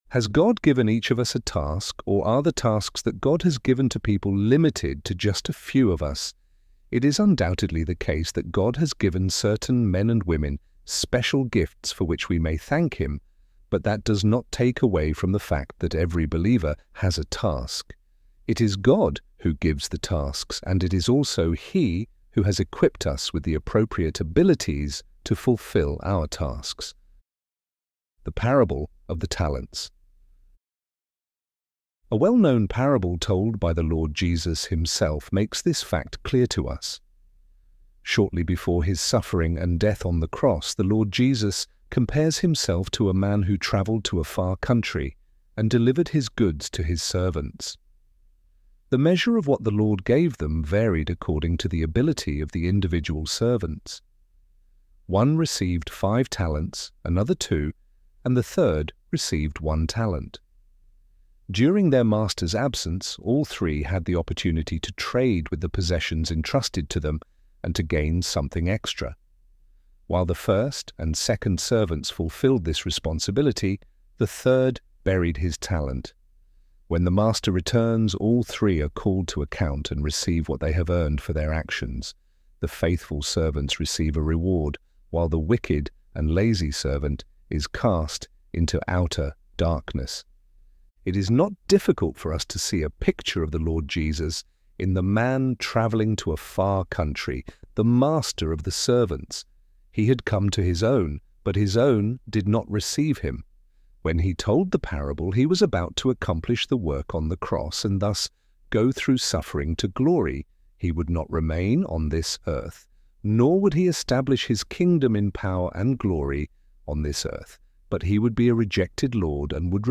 ElevenLabs_task_for_everyone_EAB.mp3